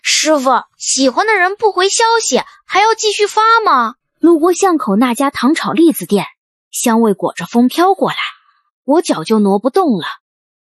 瞑想、スピリチュアルな教え、平和なナレーションのためにデザインされた、穏やかで賢明で響きのあるAI音声の力を活用してください。
テキスト読み上げ
瞑想的なペース配分
賢明なイントネーション